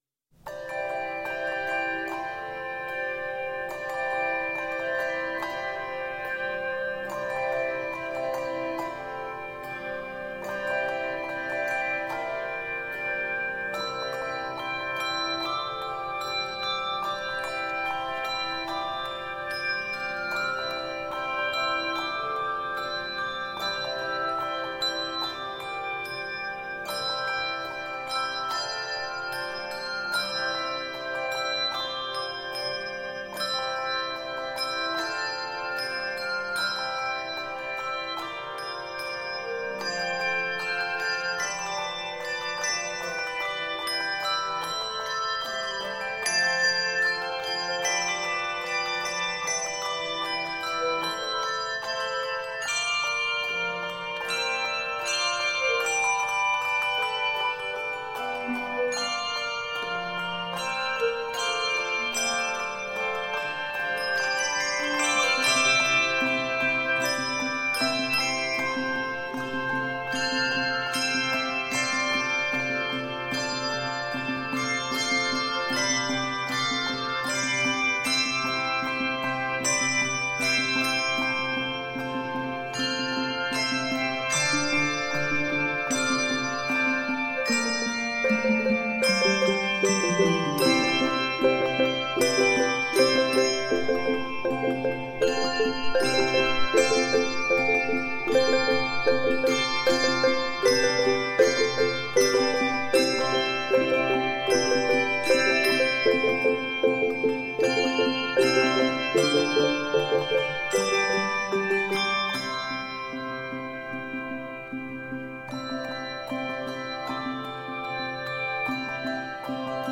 set of variations